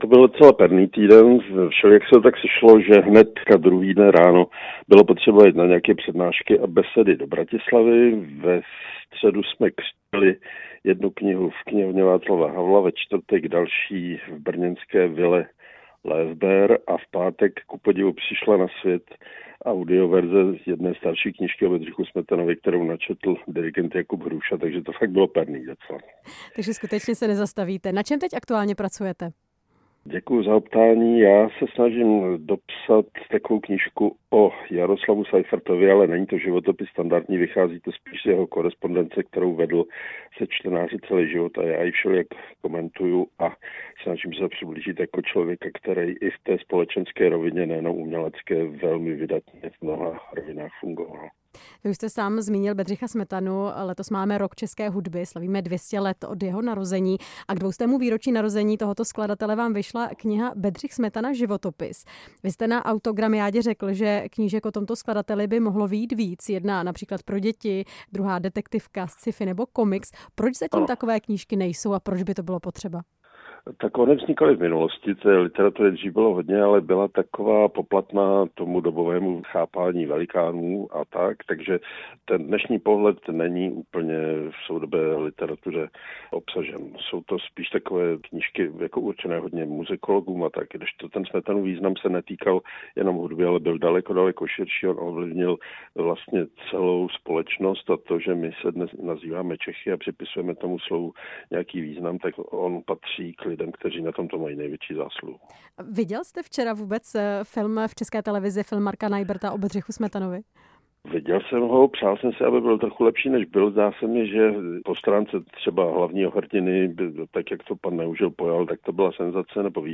Hostem vysílání Radia Prostor byl spisovatel a publicista Pavel Kosatík, oceněný prezidentem Petrem Pavlem medailí Za zásluhy o stát v oblasti kultury.